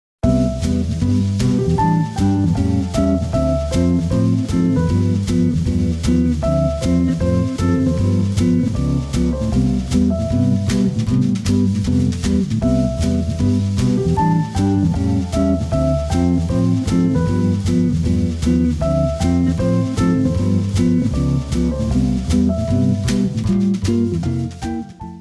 Kategoria Alarmowe